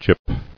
[gip]